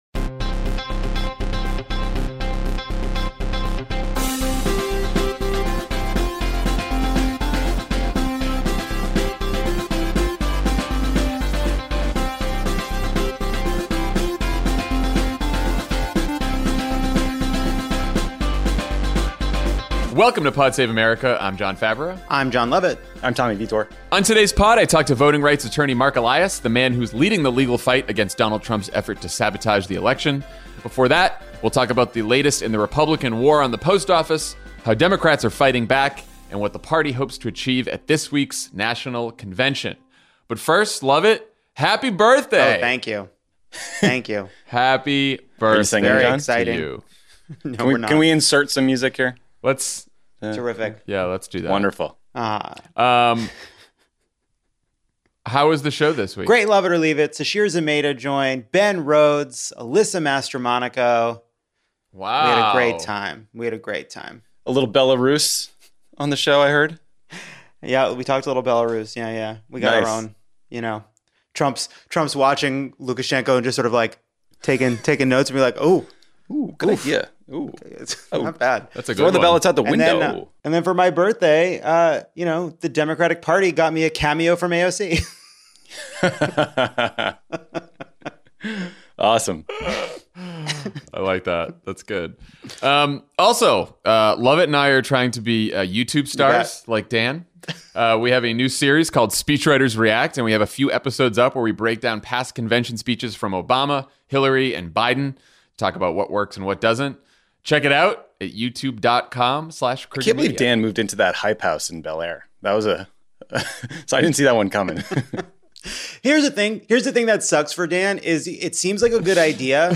The Postal Service warns states that mail-in ballots might not be counted in time, Democrats plan an emergency hearing with the Postmaster General, and the first all-virtual Democratic National Convention begins with speakers ranging from John Kasich to AOC. Then voting rights attorney Marc Elias talks to Jon Favreau about his legal fight against Donald Trump’s efforts to sabotage the election.